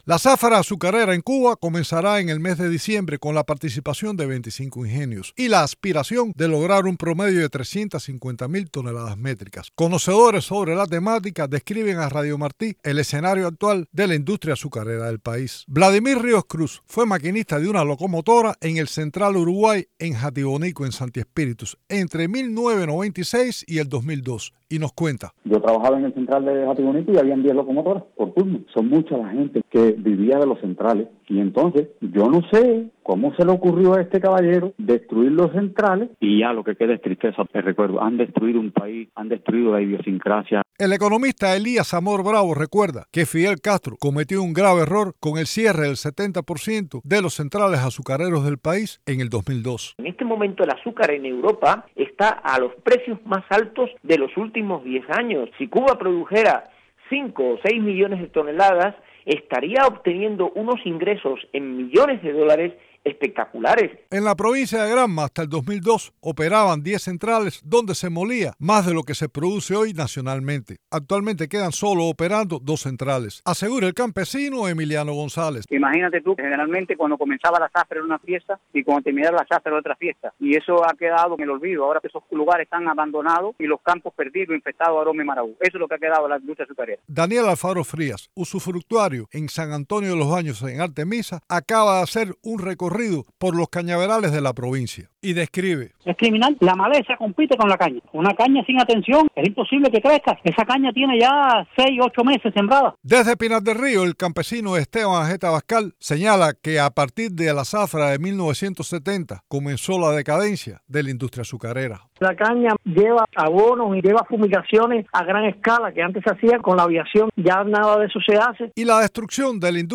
Conocedores sobre la temática describieron en entrevista con Martí Noticias, el escenario actual de la industria azucarera del país.
Testimonios de expertos sobre el estado de la industria azucarera en Cuba